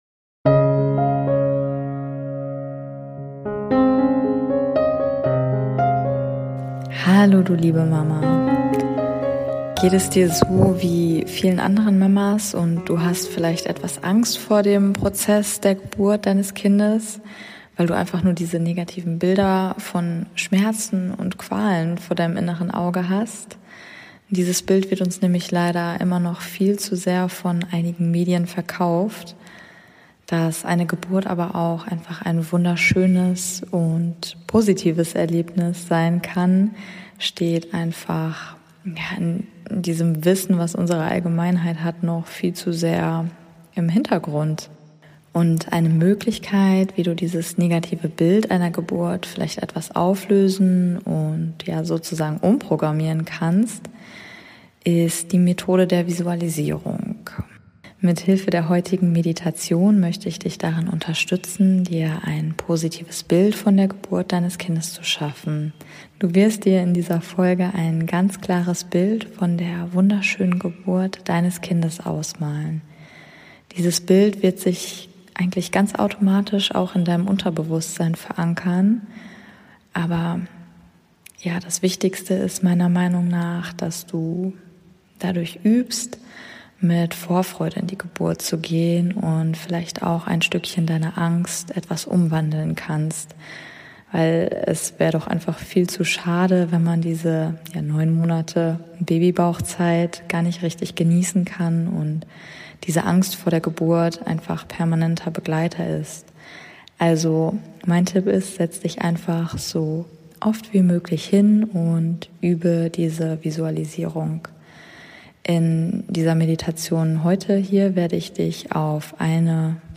#008 - Meditation Visualisiere deine Traumgeburt ~ Meditationen für die Schwangerschaft und Geburt - mama.namaste Podcast